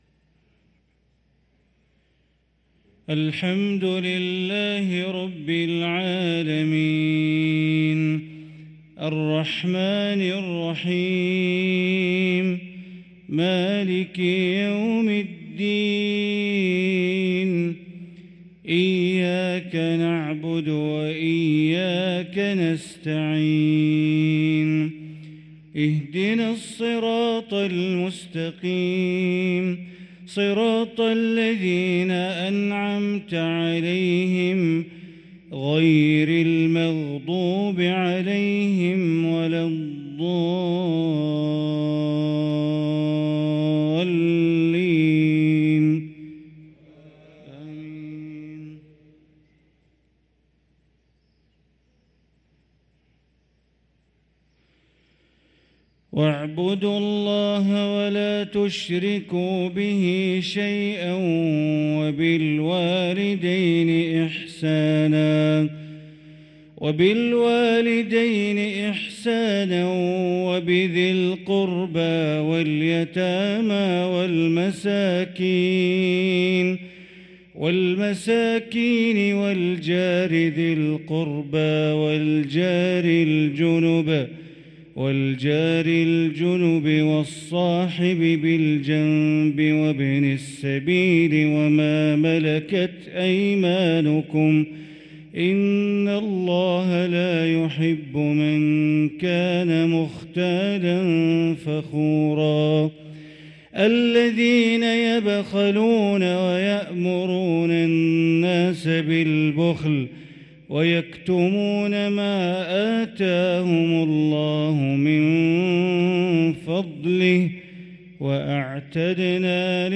صلاة المغرب للقارئ بندر بليلة 29 ربيع الأول 1445 هـ
تِلَاوَات الْحَرَمَيْن .